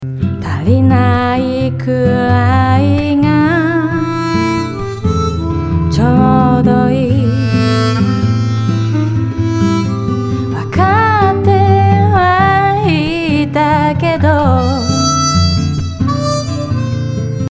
2kHzから上を持ち上げるようにしてみました。
ここを強調すること多くの反射音が聴こえてくるようになり、明るさや部屋の材質のイメージが変わってきますね。